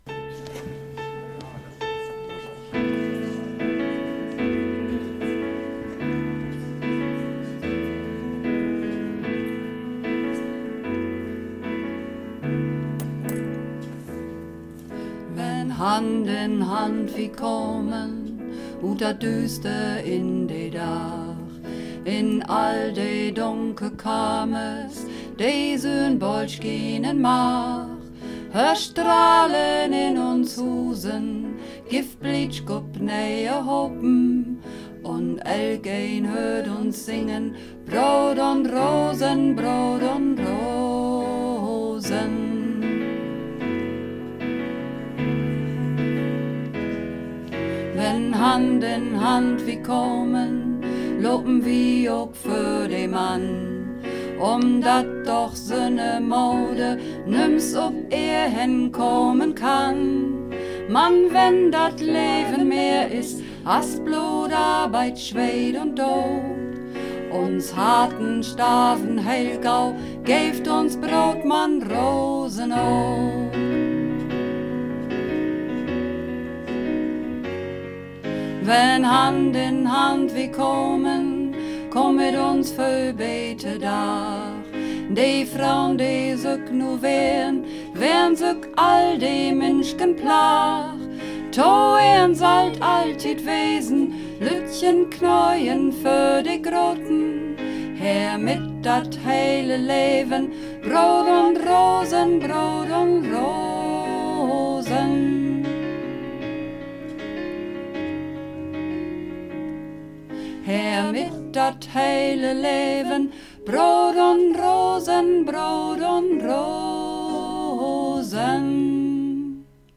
Übungsaufnahmen - Brood un Rosen
Brood un Rosen (Mehrstimmig)
Brood_un_Rosen__3_Mehrstimmig.mp3